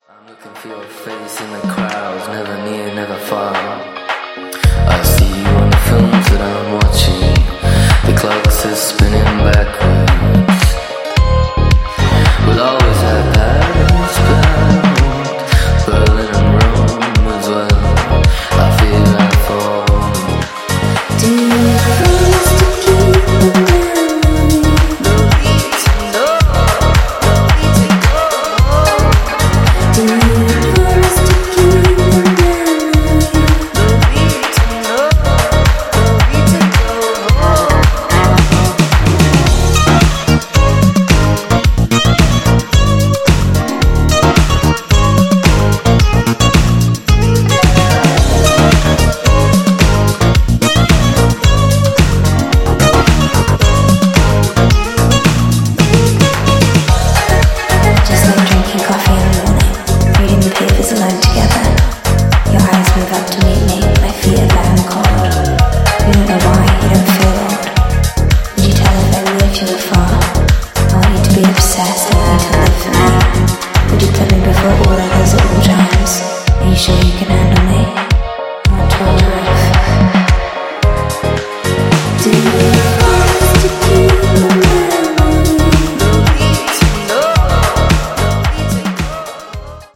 原曲のフレンチ・ポップ感を活かしたグルーヴィーなバレアリック・ディスコを披露！
ジャンル(スタイル) DEEP HOUSE / NU DISCO / BALEARIC